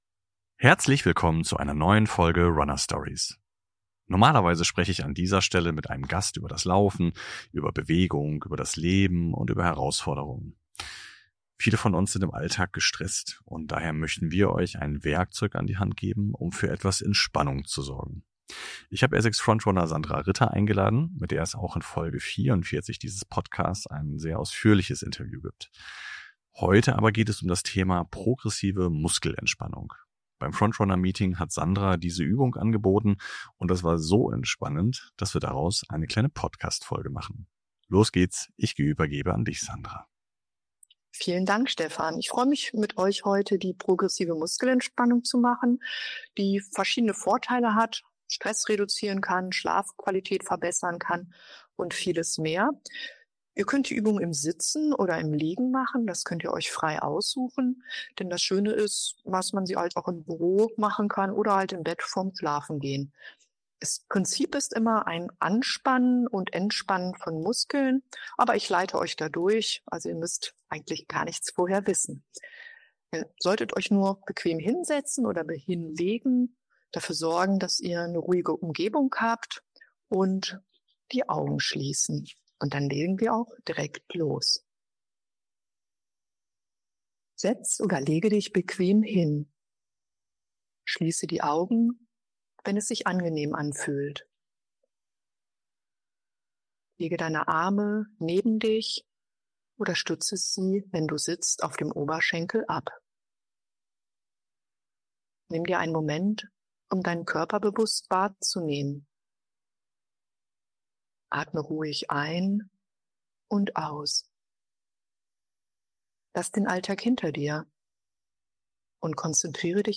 Heute gibt es für Euch allerdings eine praktische Übung.